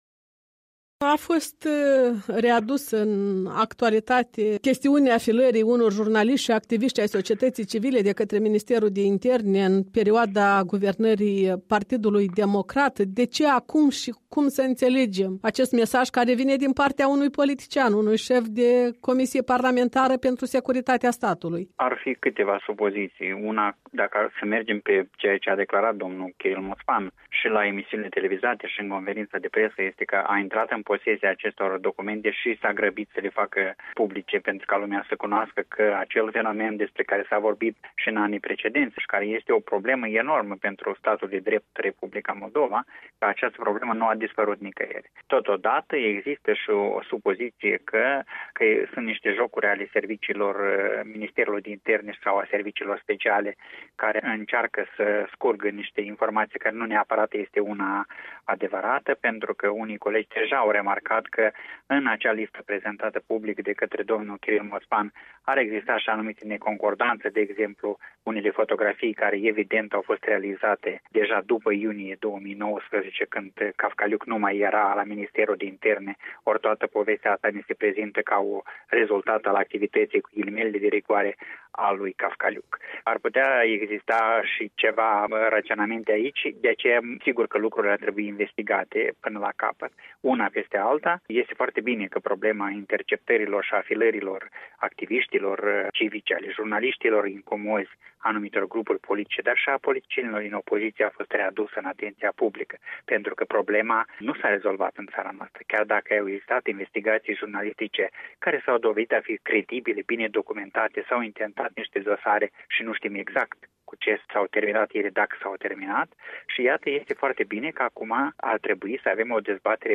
în dialog cu